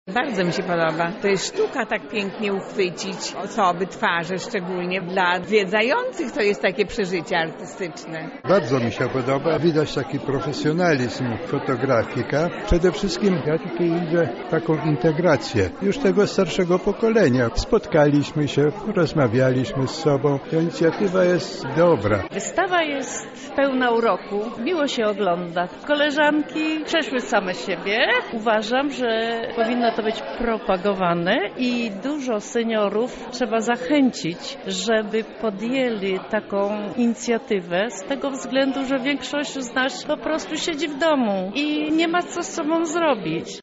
Nasz reporter zapytał uczestników o wrażenia w trakcie wernisażu.